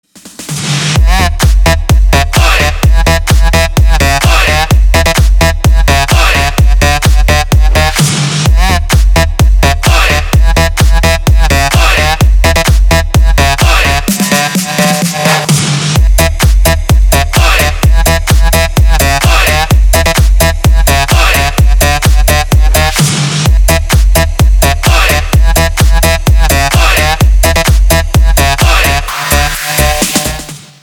• Качество: 320, Stereo
громкие
EDM
электронная музыка
без слов
клубняк
танцевальные
Melbourne Bounce